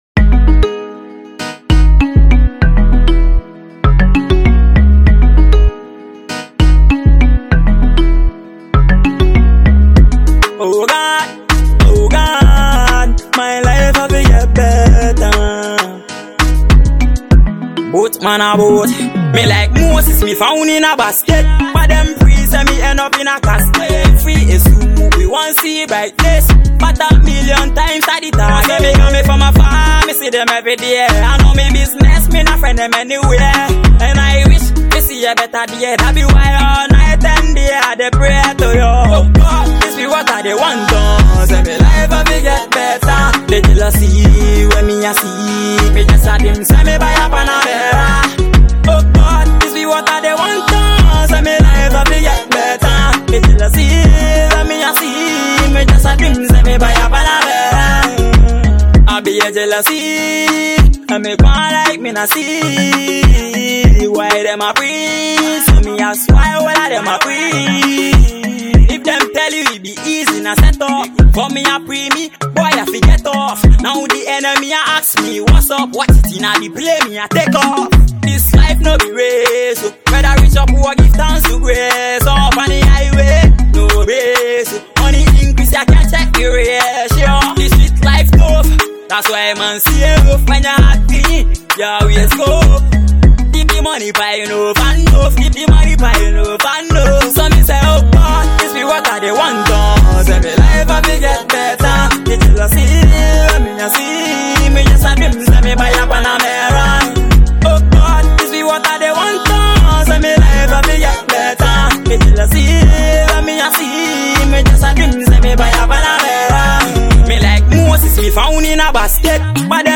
Ghanaian Dancehall